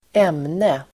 Ladda ner uttalet
Uttal: [²'em:ne]